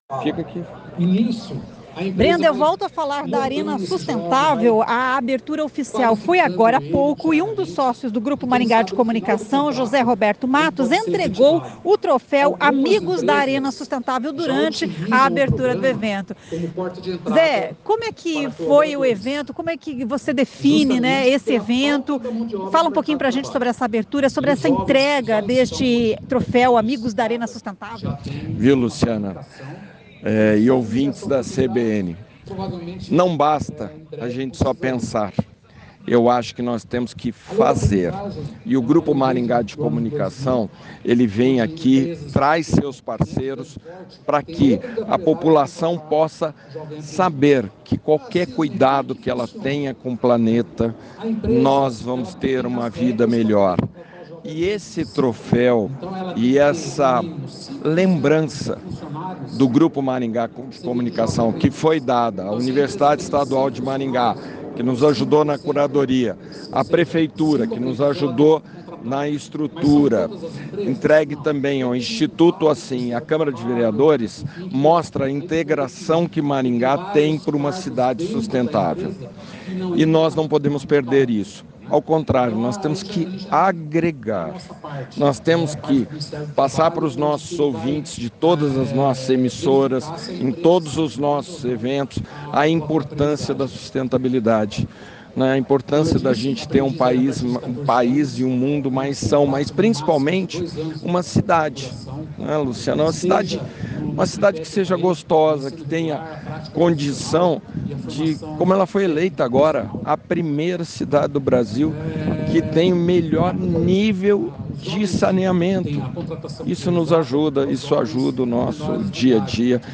A entrega foi durante a cerimônia de abertura do evento na manhã desta sexta-feira (7).